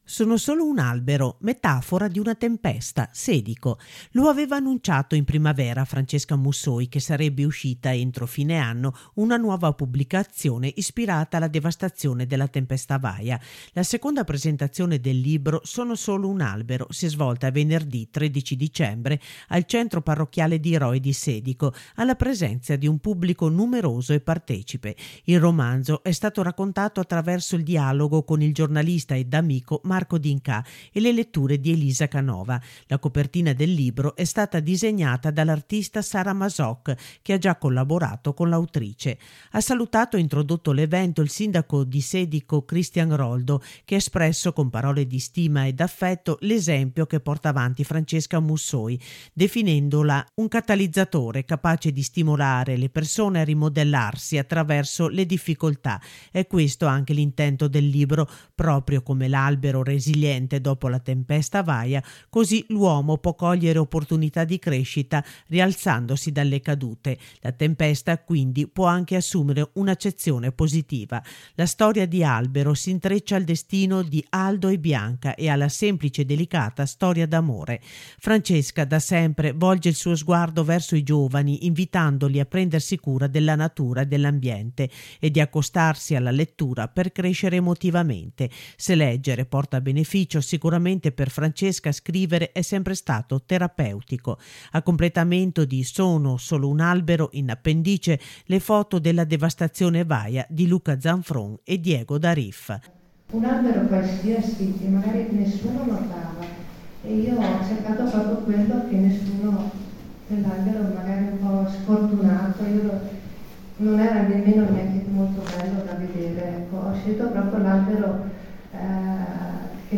La seconda presentazione del libro “sono solo un albero” si è svolta venerdì 13 dicembre al centro parrocchiale di Roe di Sedico alla presenza di un pubblico numeroso e partecipe.